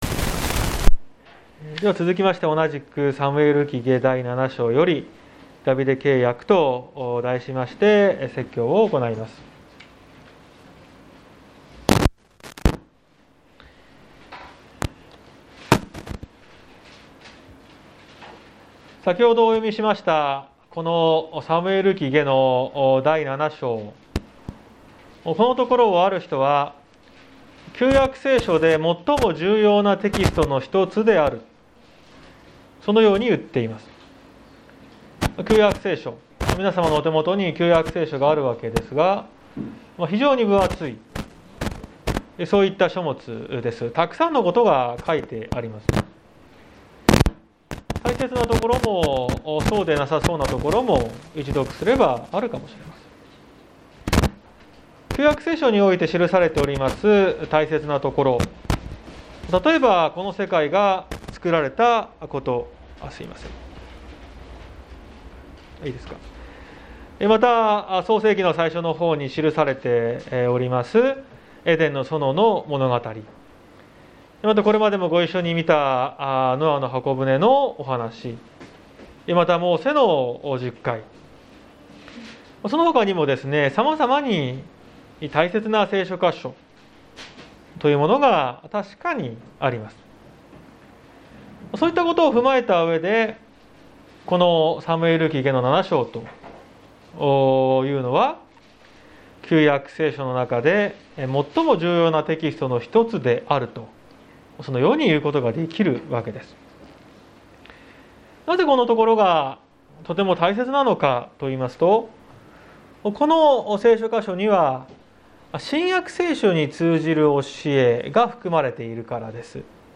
綱島教会。説教アーカイブ。